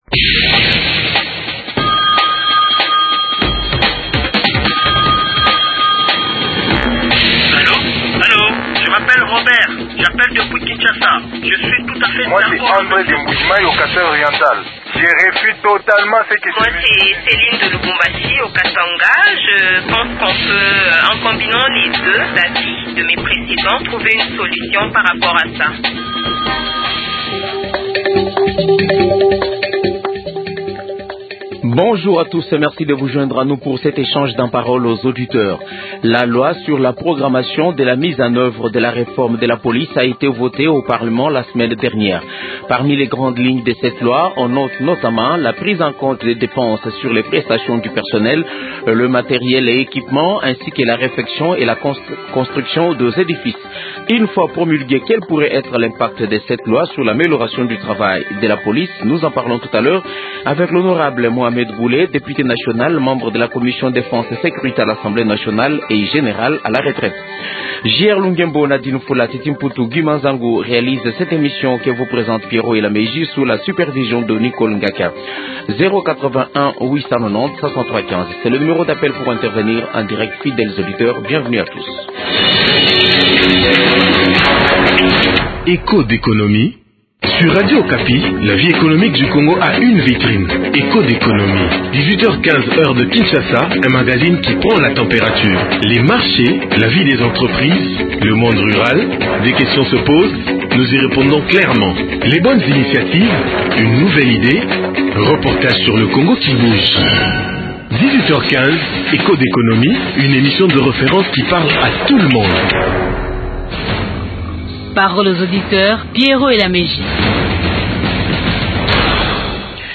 Invité : Mohamed Bule, député national membre de la commission défense et sécurité à l’Assemblée nationale